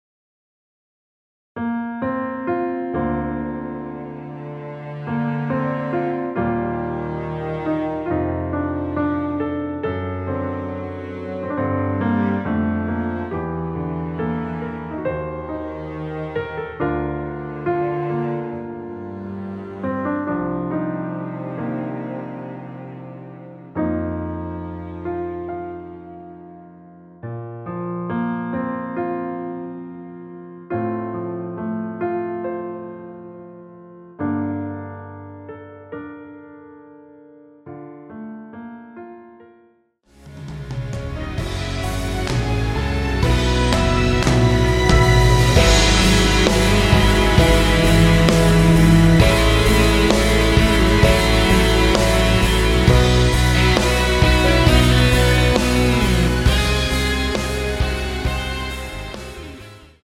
원키에서(-1)내린 MR입니다.
Bb
앞부분30초, 뒷부분30초씩 편집해서 올려 드리고 있습니다.
중간에 음이 끈어지고 다시 나오는 이유는